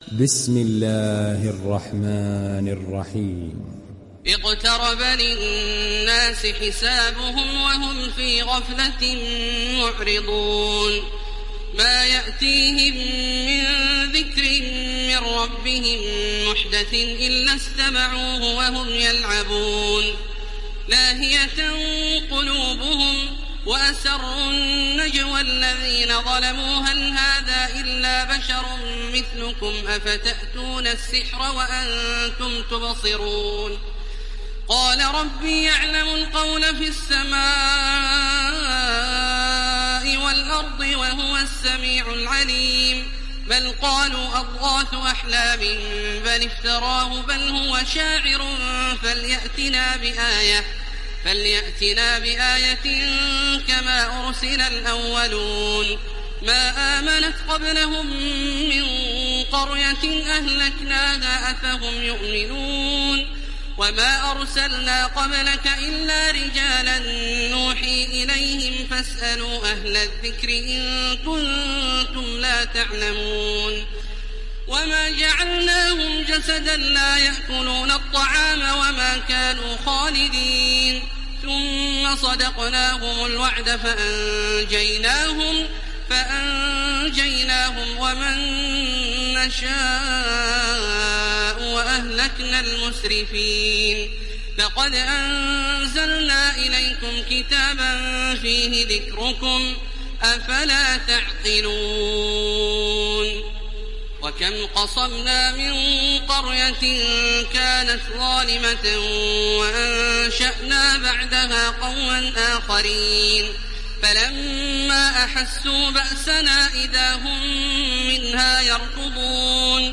Surah Al Anbiya Download mp3 Taraweeh Makkah 1430 Riwayat Hafs from Asim, Download Quran and listen mp3 full direct links
Download Surah Al Anbiya Taraweeh Makkah 1430